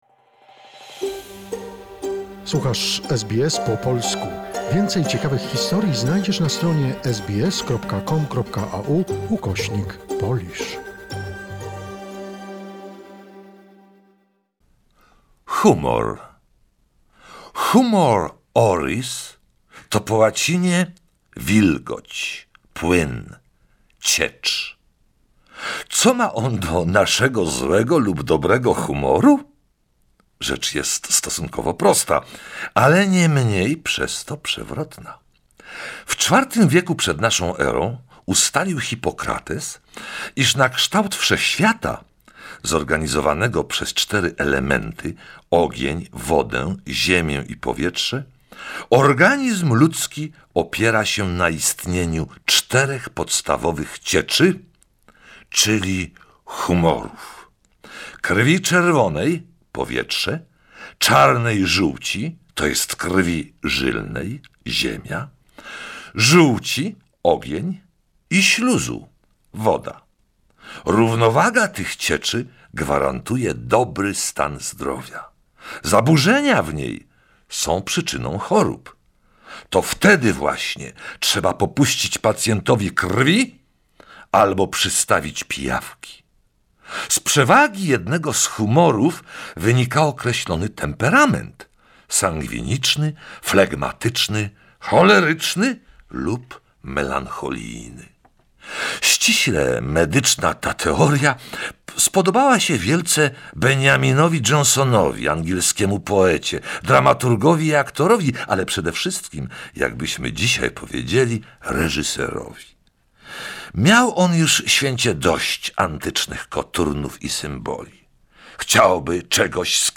We present archival recordings of fragments of the book